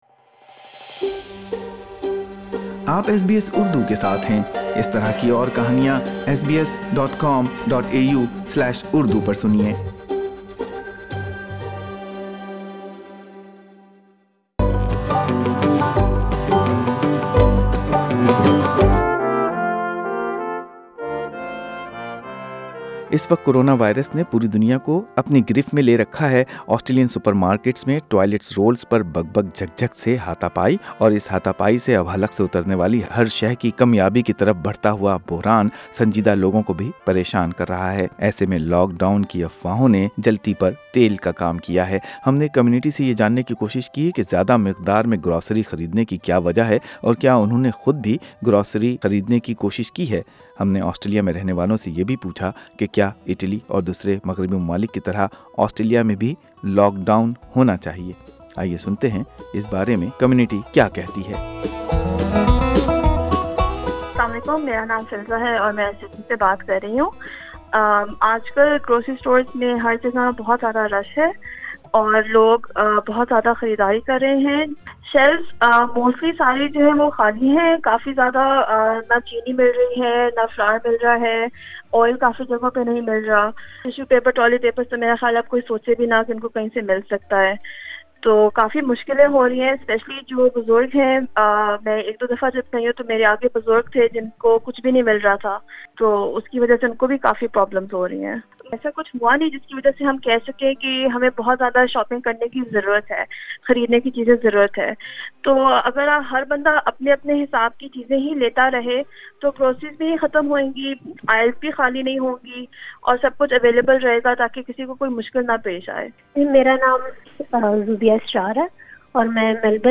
Out of control infection, fear of isolation, shortage of essentials and looming recession are few of the impacts that an ordinary Aussie is worried about. Besides all, fear of lock-down is in the subconscious of every Australian but recent talk-back revealed that the migrants are sharply divided on the option of Australian's lock-down.